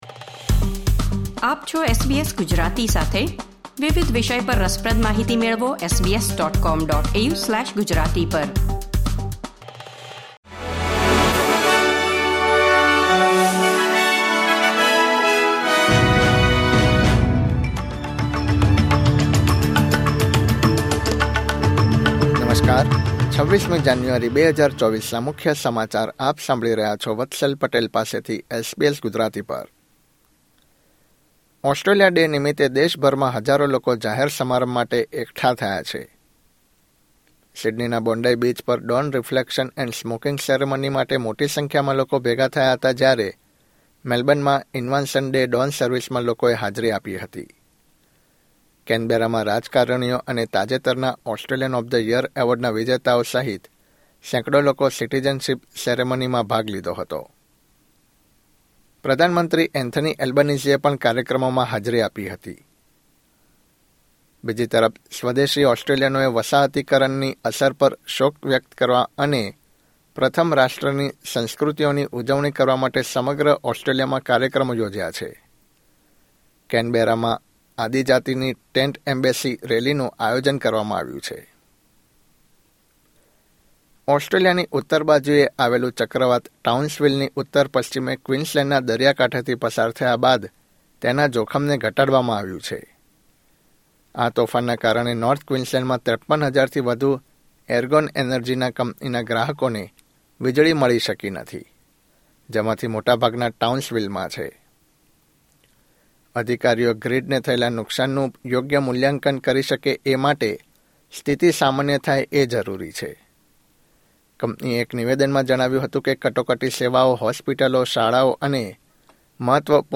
SBS Gujarati News Bulletin 26 January 2024